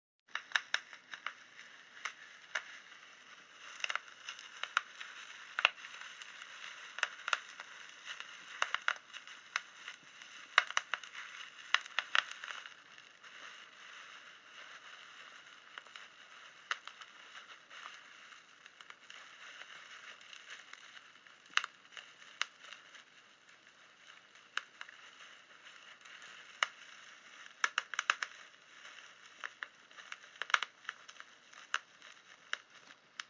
Bubble Wrap
Location: My dorm room Sound: Me popping bubble wrap from my package
Sound: Me popping bubble wrap from my package